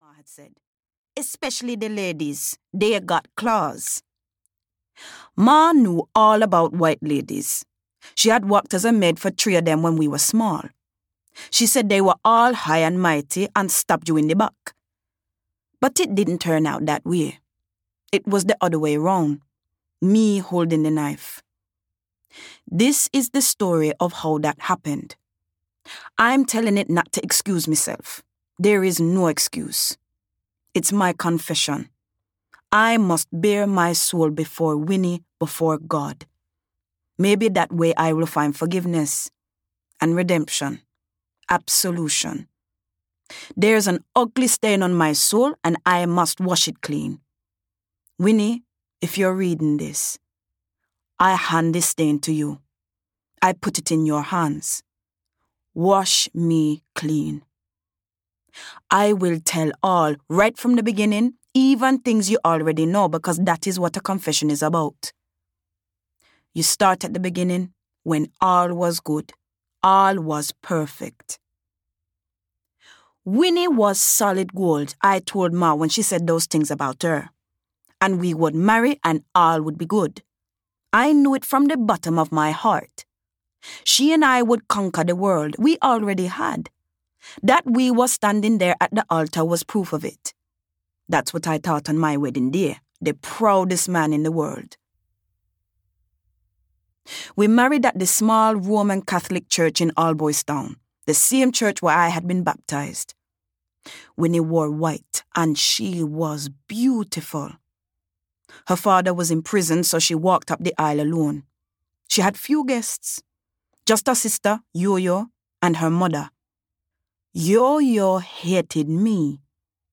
The Sugar Planter's Daughter (EN) audiokniha
Ukázka z knihy